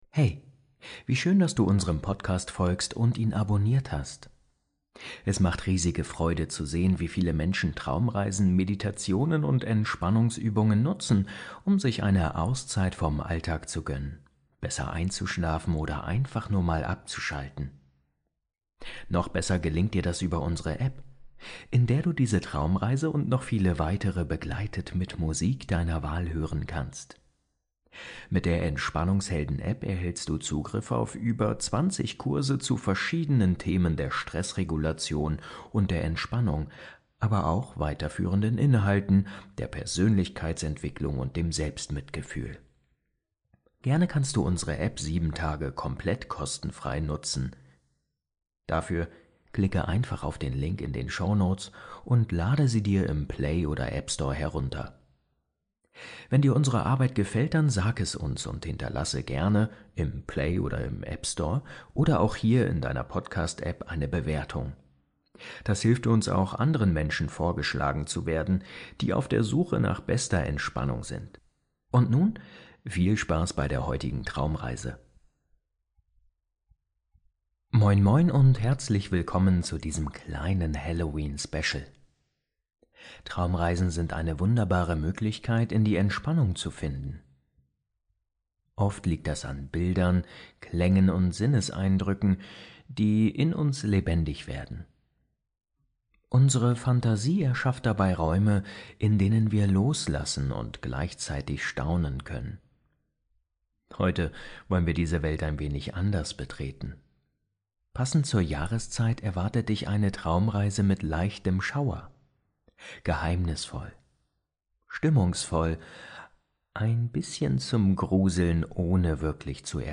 Halloween Traumreise - Masken die du trägst ~ Entspannungshelden – Meditationen zum Einschlafen, Traumreisen & Entspannung Podcast